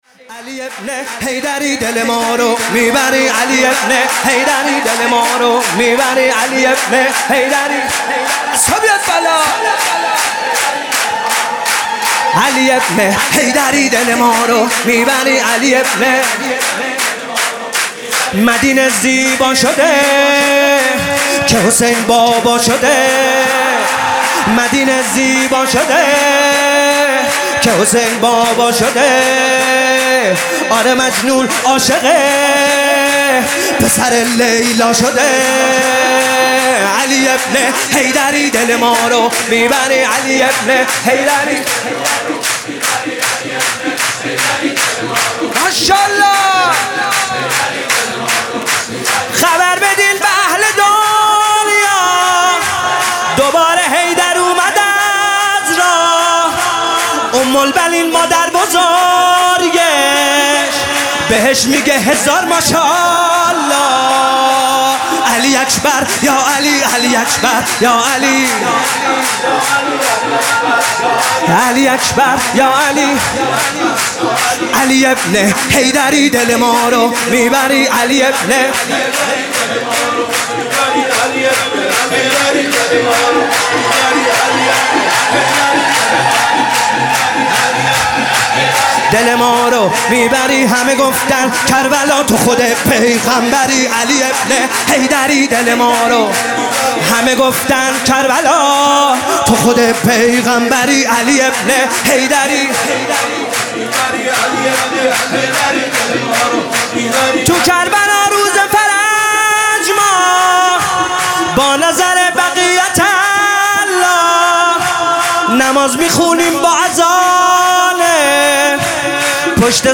سرود: علی ابن حیدری، دل مارو میبری